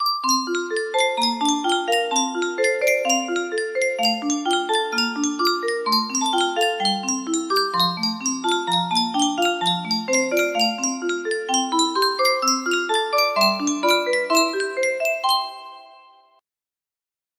Yunsheng Music Box - Jeanie with the Light Brown Hair 1710 music box melody
Full range 60